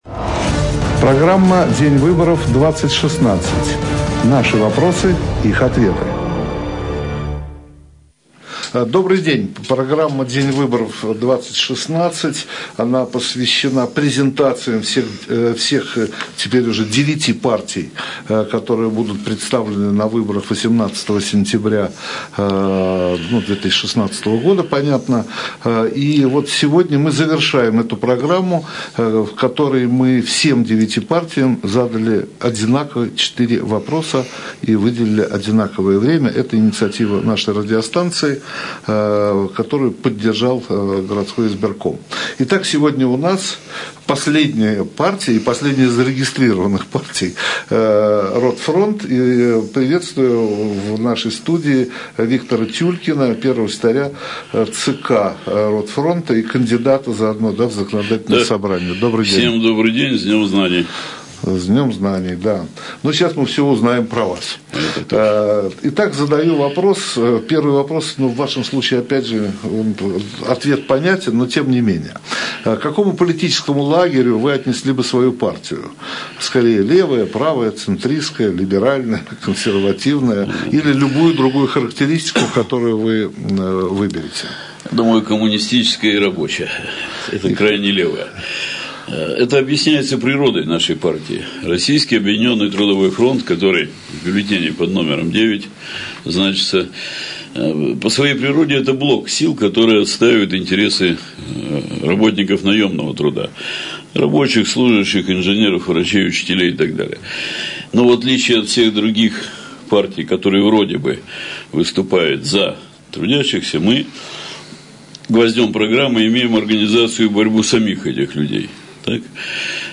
Интервью В. А. Тюлькина радио "Эхо Москвы" (Ленинград).